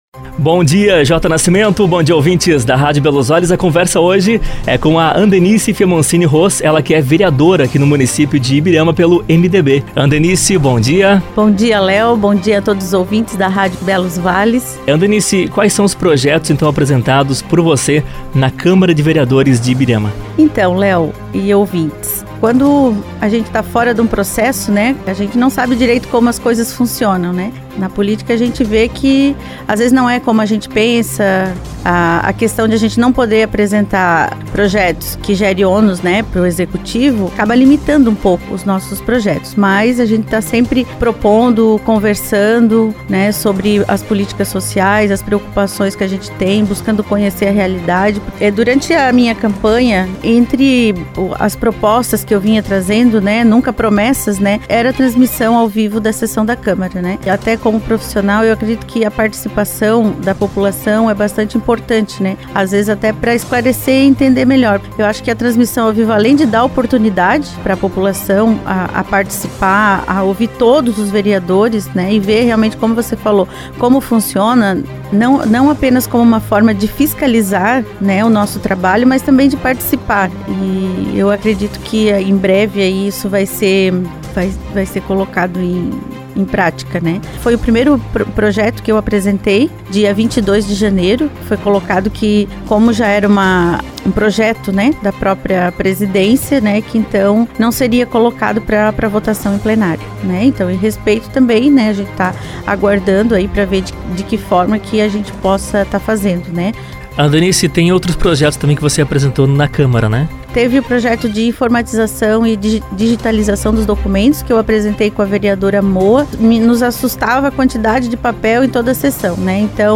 Entrevista com a Vereadora Andenice Fiamoncini Roos